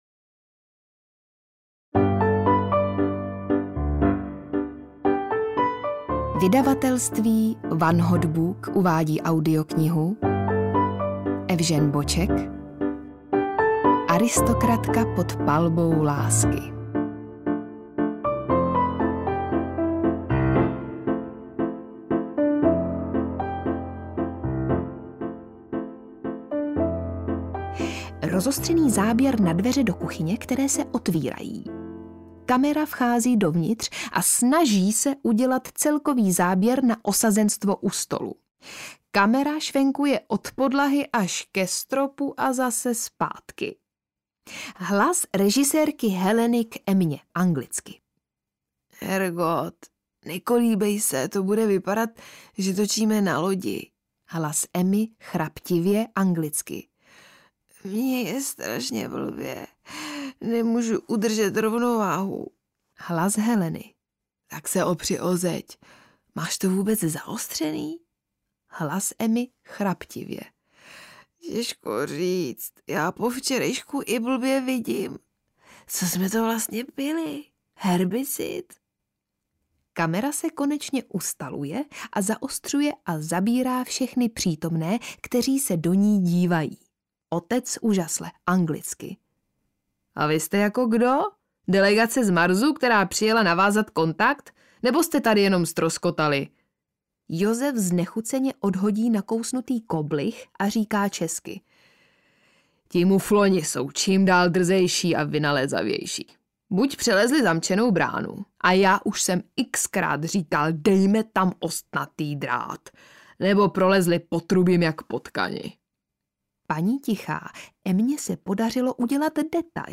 Aristokratka pod palbou lásky audiokniha
Ukázka z knihy